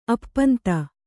♪ appanta